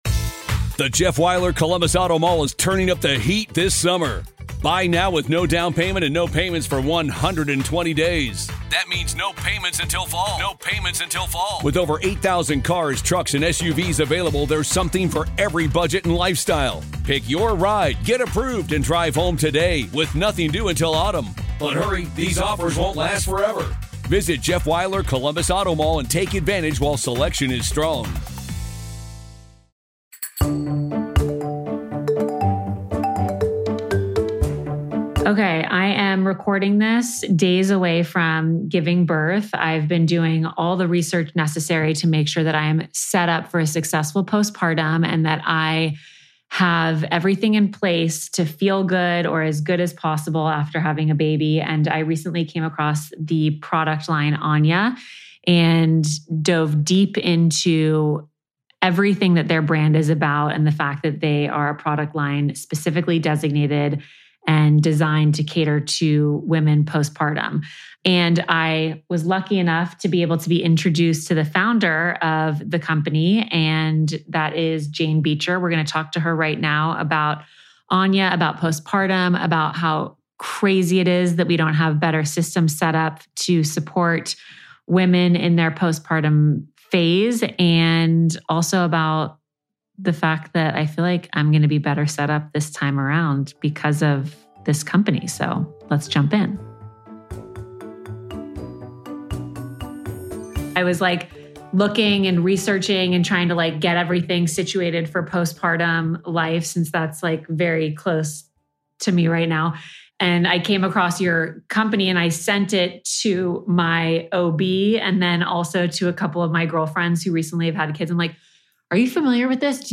This episode was recorded before my baby arrived, and I’m so excited to finally share it with you!